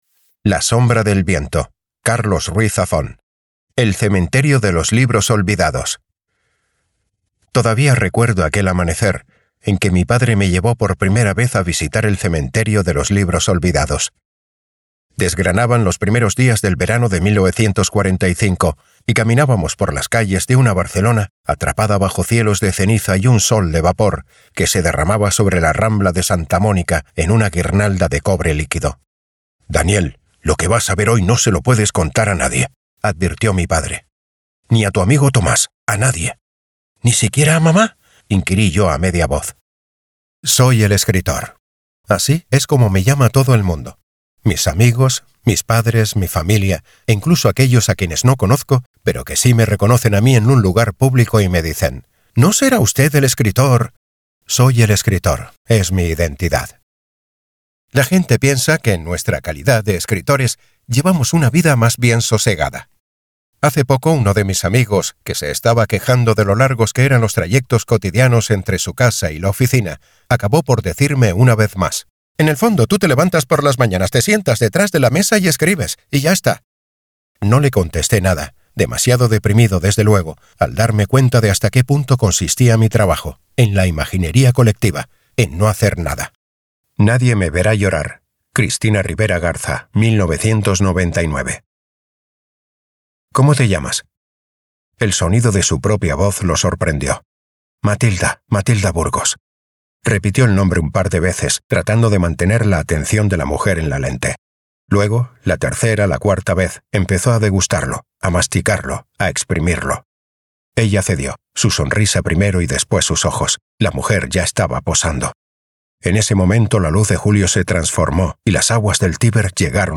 Audiobook
castilian
Middle Aged
DEMO AUDIOLIBROS.mp3